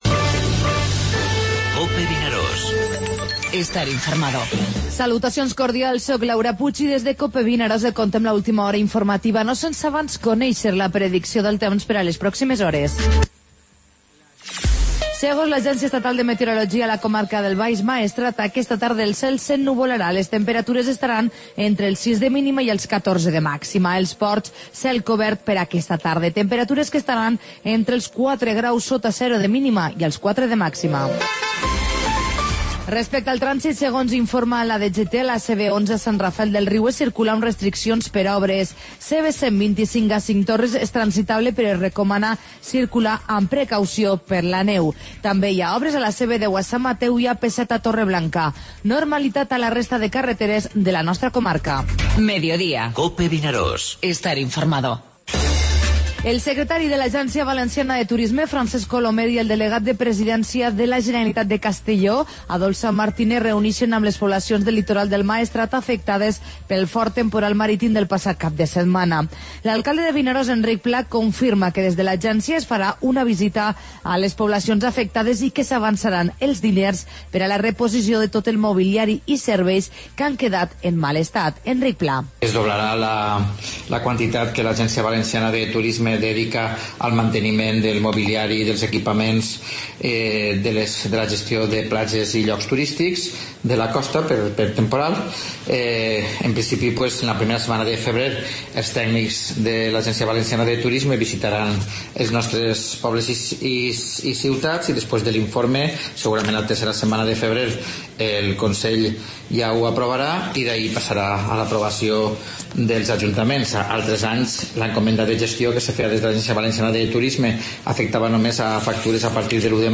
Informativo Mediodía COPE al Maestrat (dimecres 25 de gener)